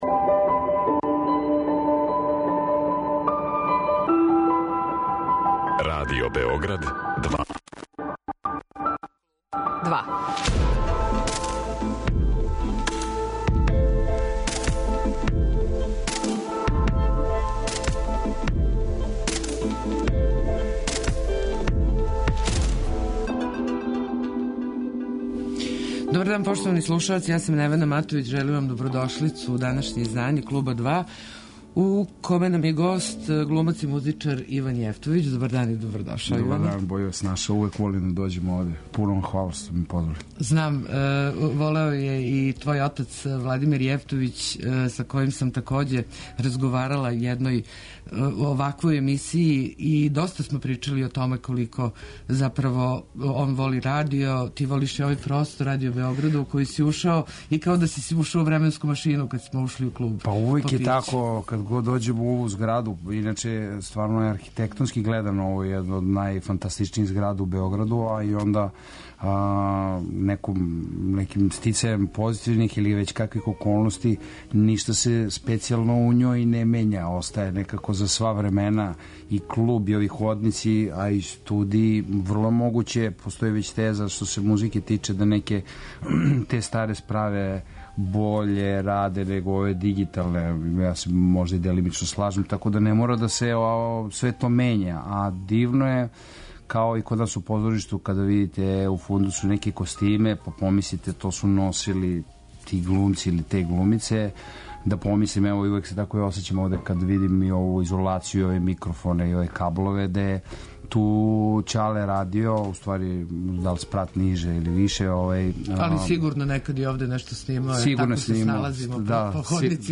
Он је и гост данашњег издања емисије Клуб два, у којој ћемо разговарати о значају ове манифестације, као и о томе колико је филм медиј који се може „искористити" као средство едукативног деловања.